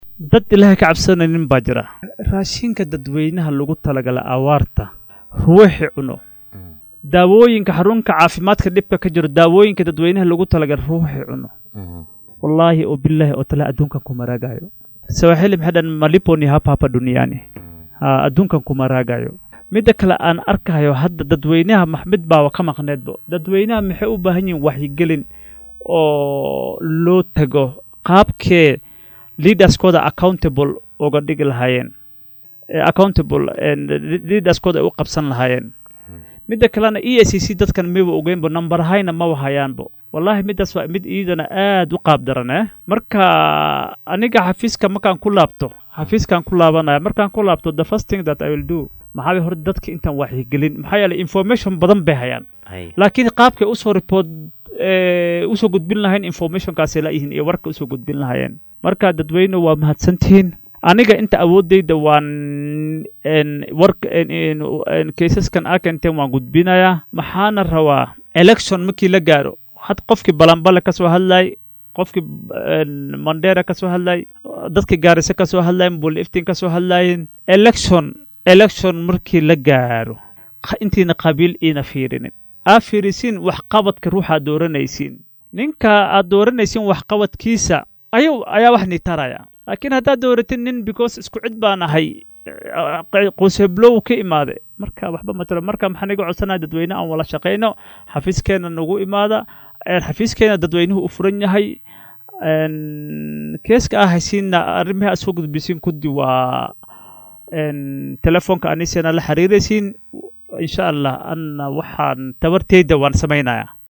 Sarkaal sare oo ka tirsan guddiga anshaxa iyo ladagaalnka Musuqmaasuqa ee EACC oo saaka marti ku ahaa barnaamijka Hoggaanka Star ayaa inooga warbixiyay waxyaabo badan oo ku saabsan la dagaalanka Musuqmaasuqa.